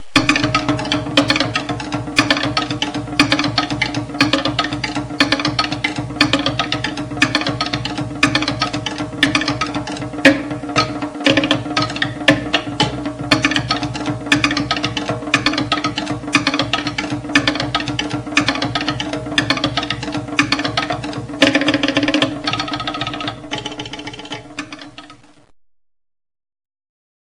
Ecoute >> Le to'ere :
Instrument à percussion ...
Le son du to’ere est fonction du bois utilisé et de sa taille.
Plus l'on frappe vers l'extrémité, plus le son est sec et aigu.
Mesure à 4 temps, rythme binaire, variation